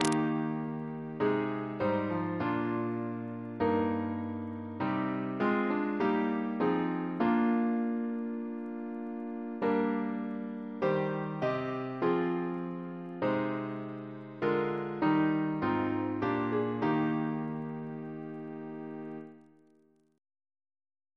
Double chant in E♭ Composer: Thomas Attwood Walmisley (1814-1856), Professor of Music, Cambridge Reference psalters: ACB: 318